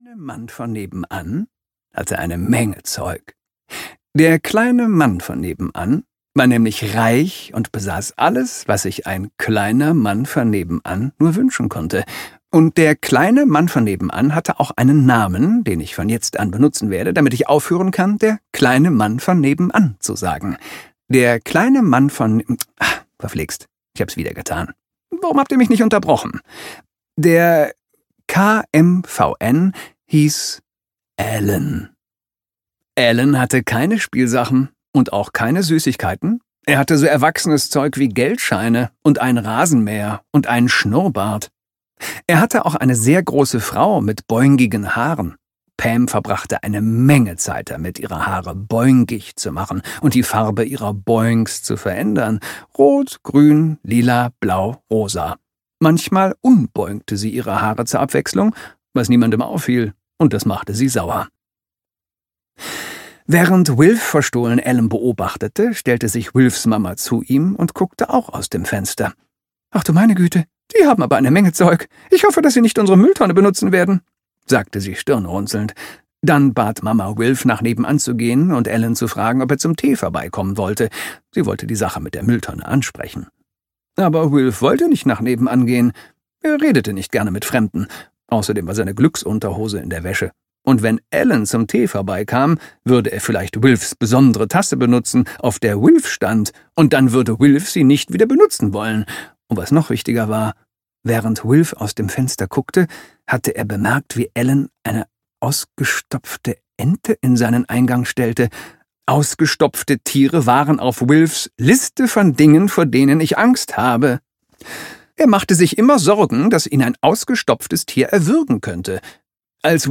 Wilf plötzlich Held - Georgia Pritchett - Hörbuch